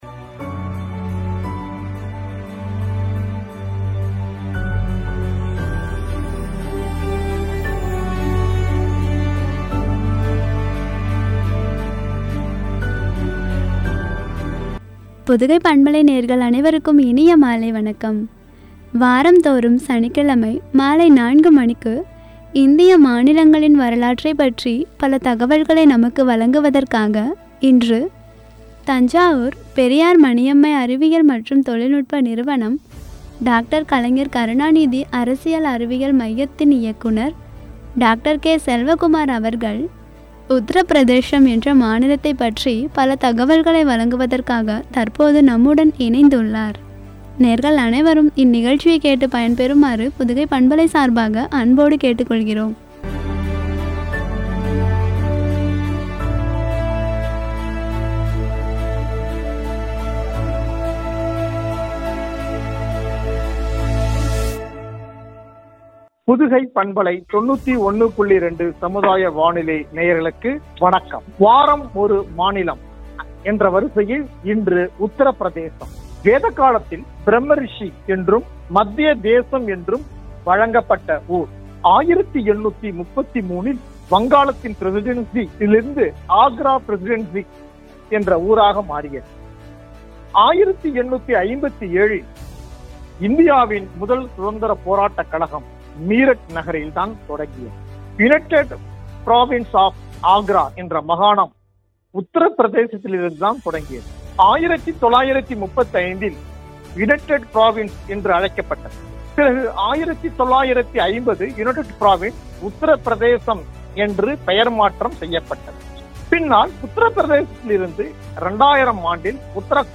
உரை